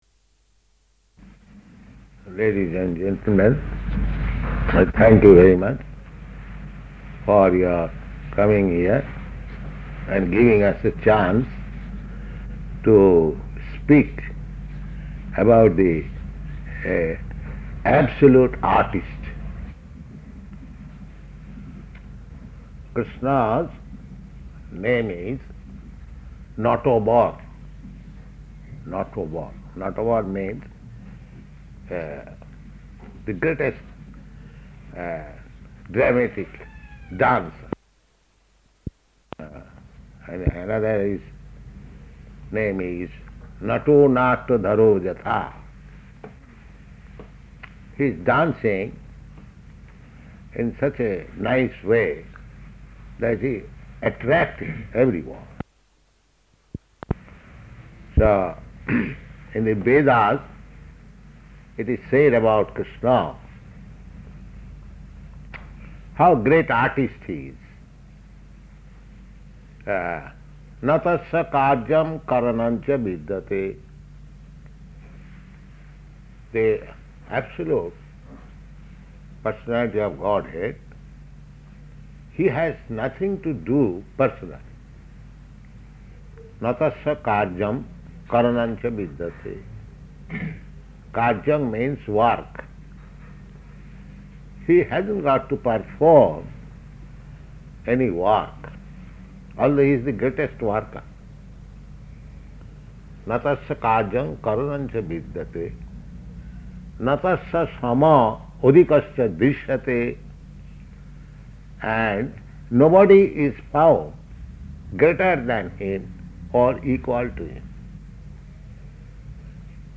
Lecture at Art Gallery
Type: Lectures and Addresses
Location: Auckland